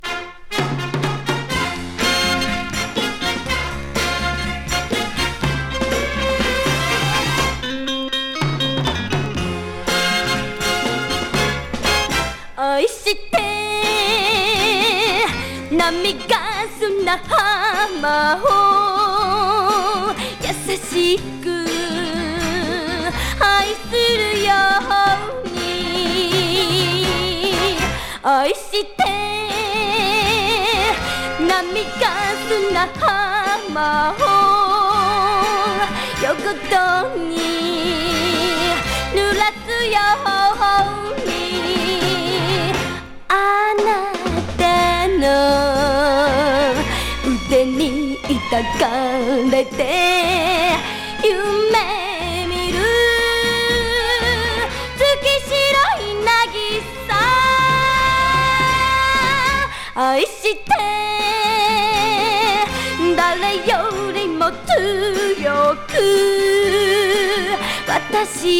情熱的でソウルフルな歌声が魅力！タイトなドラムのファンキー歌謡